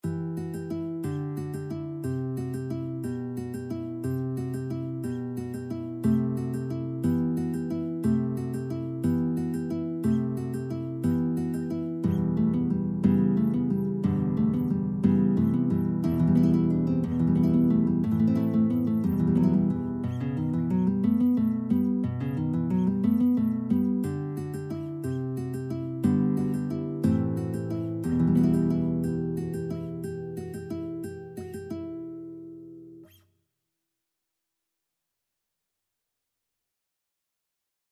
Free Sheet music for Guitar Duet
E minor (Sounding Pitch) (View more E minor Music for Guitar Duet )
3/4 (View more 3/4 Music)
Traditional (View more Traditional Guitar Duet Music)
carol_of_the_bells_2GUIT.mp3